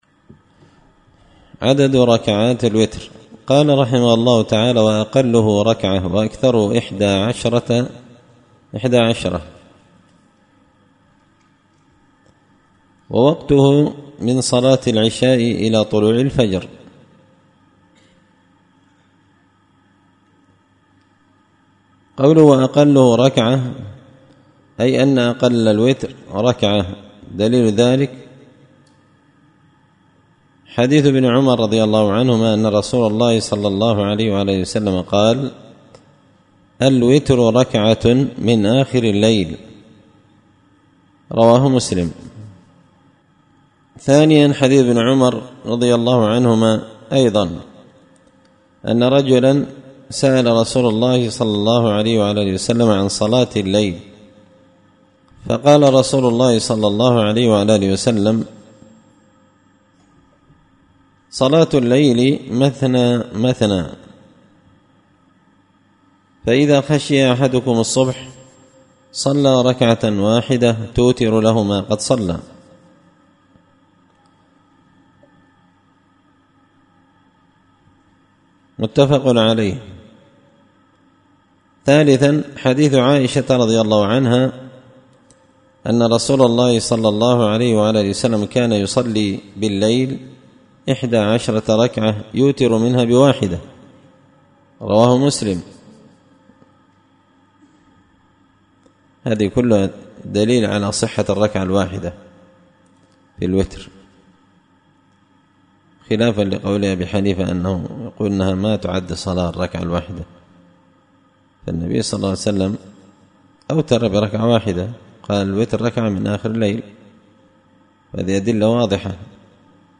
الدرس السابع عشر
دار الحديث بمسجد الفرقان ـ قشن ـ المهرة ـ اليمن